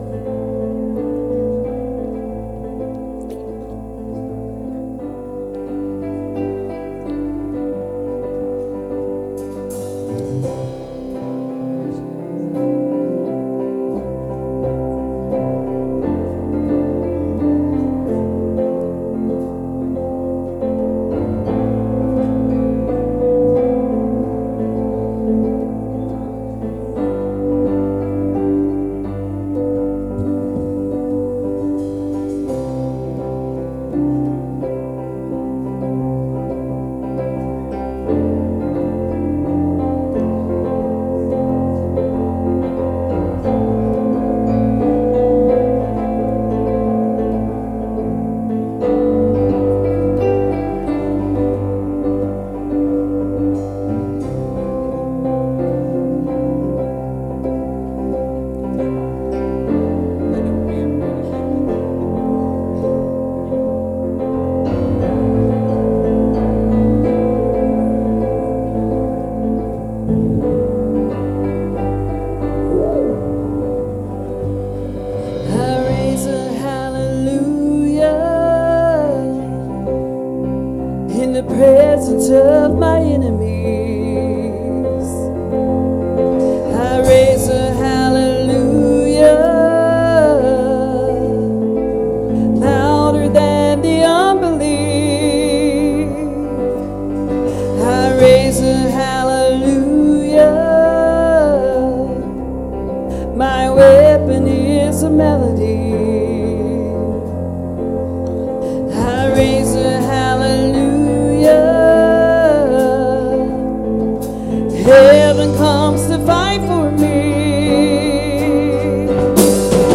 Passage: "Jeremiah 6:16-19" Service Type: Sunday Morning Services « Jesus’s Mission Statement Believe And Have Faith